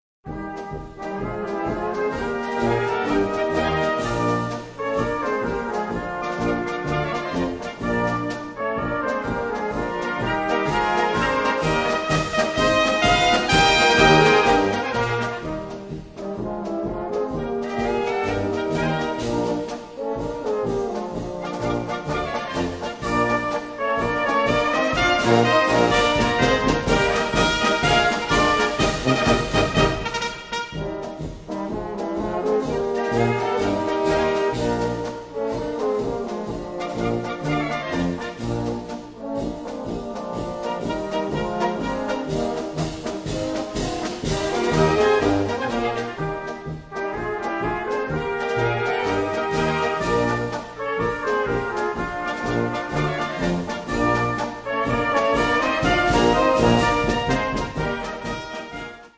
Gattung: Mährische Polka
Besetzung: Blasorchester